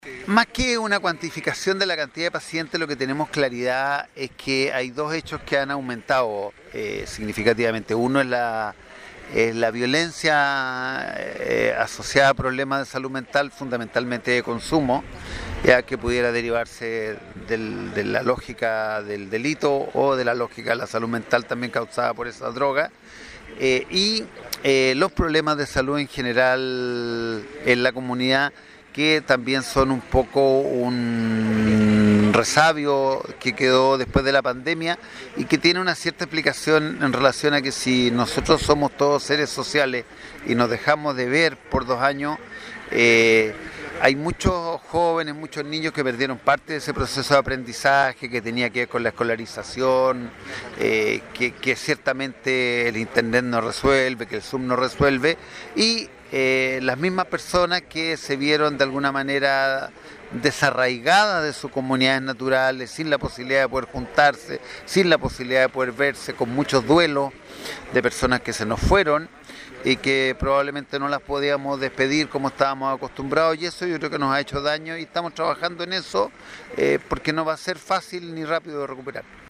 Las condiciones insalubres, como el estigma, la discriminación y la exposición a riesgos como el acoso y otras malas condiciones de trabajo, pueden plantear riesgos importantes que afecten la salud mental, la calidad de vida en general y, en consecuencia, la participación o la productividad en el trabajo, señaló el Director Provincial del Servicio de Salud